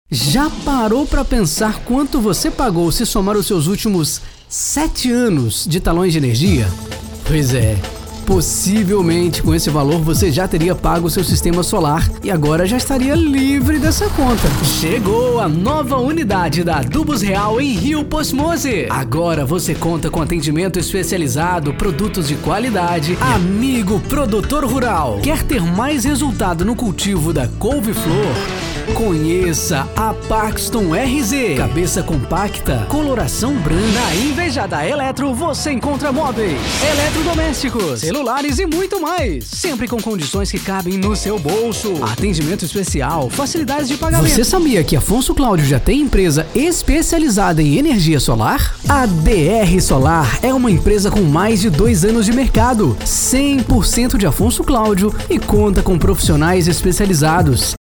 Demo Padrão :